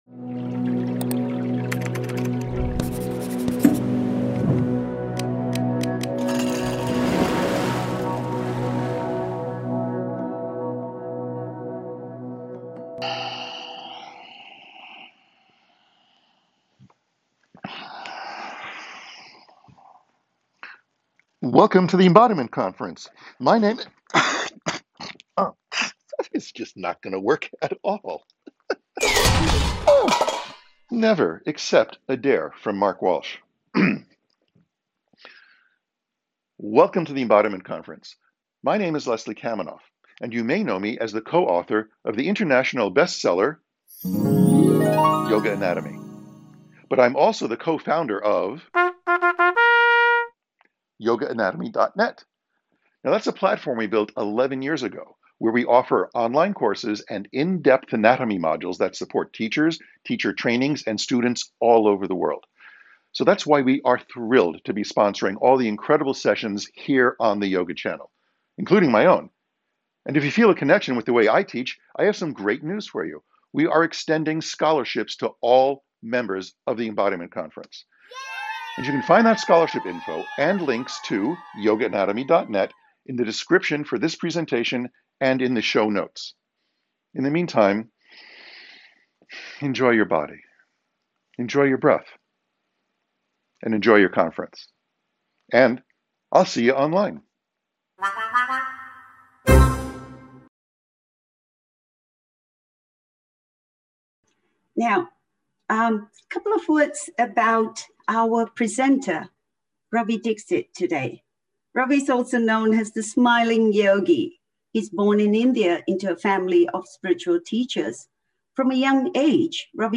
Guided Practices